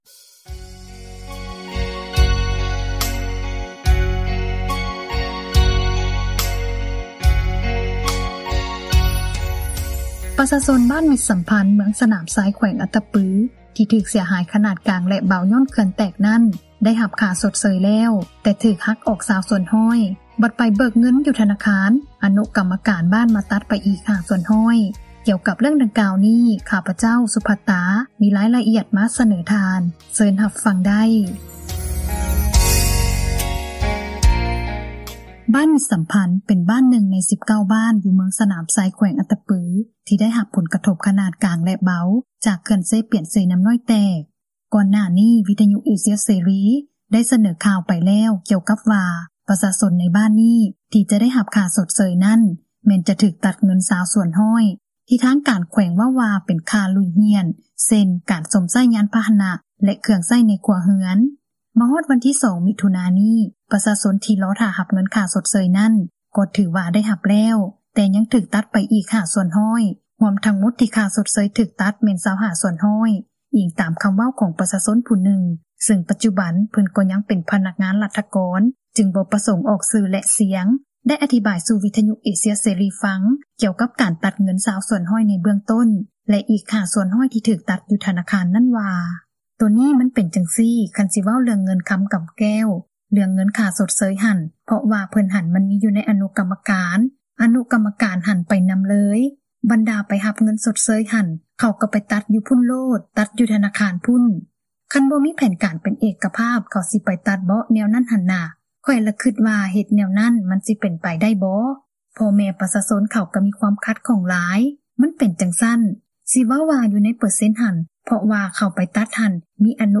ຊາວບ້ານມິດສໍາພັນ ຖືກຫັກ ຄ່າຊົດເຊີຍ – ຂ່າວລາວ ວິທຍຸເອເຊັຽເສຣີ ພາສາລາວ